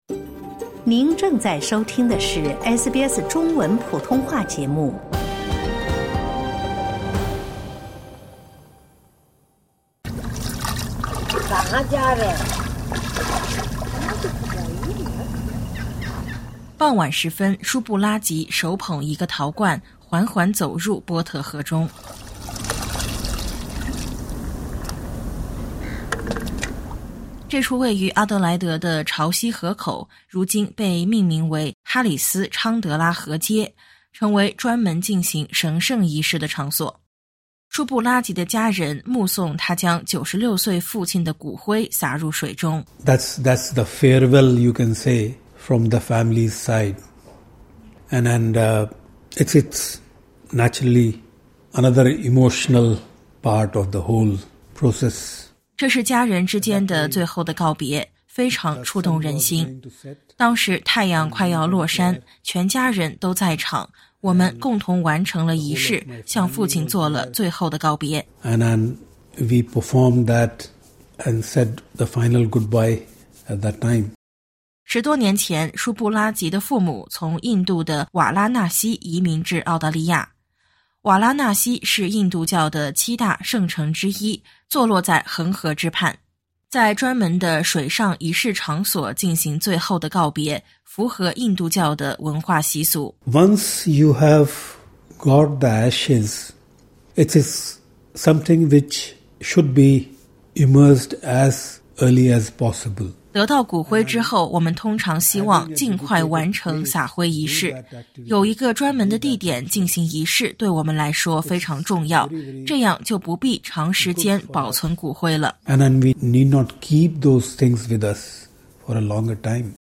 经过多年的努力，南澳的一处印度教社区终于获得了批准，设立了一个专门用于宗教撒灰仪式的地点。点击音频，收听综合报道。